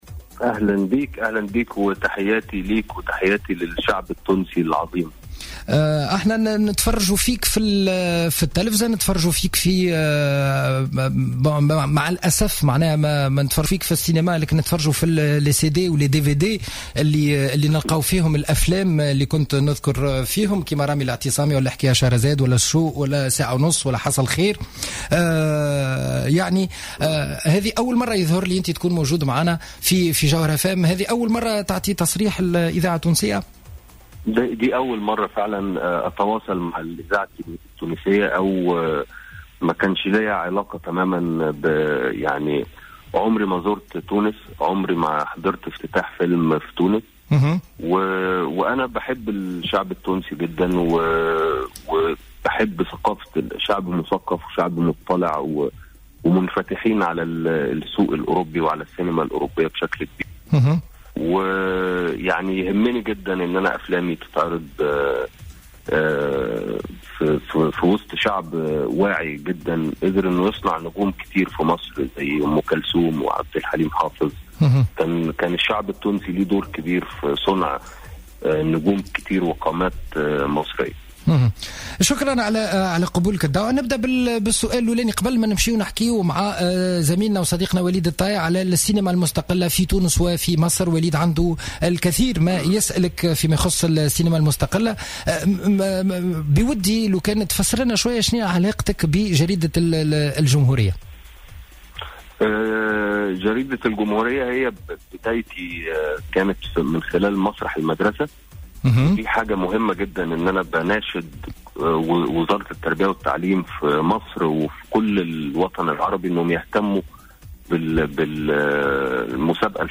محمد رمضان، أو "أحمد زكي الجديد" كما يسميه البعض أكد أنه ورغم افتخاره بهذا اللقب إلا أن أعماله لا تحمل نفس "الطابع" الذي اعتمده امبراطور السينما العربية، مشيرا إلى أن هذه التسميات لن تصيبه بالغرور، أو كما قال بلهجته المصرية "مش هغلط في نفسي".